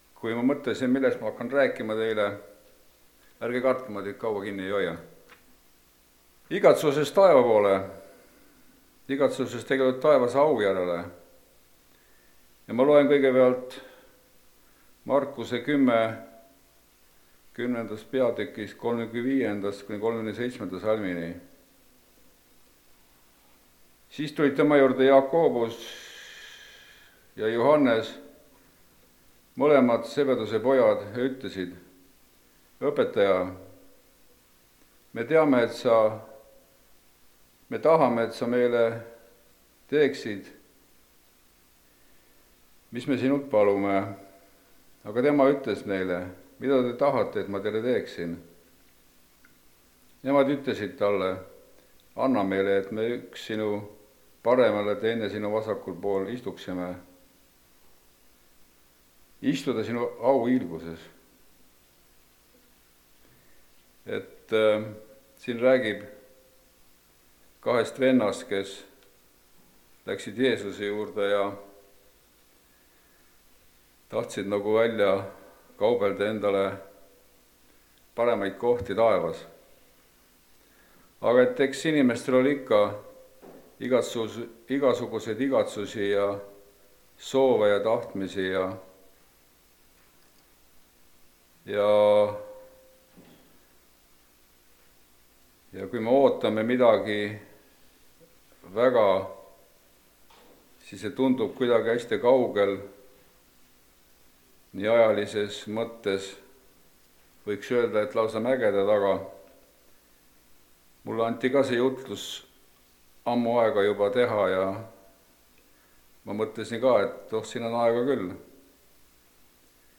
Igatsus taevase au järele (Rakveres)
Jutlused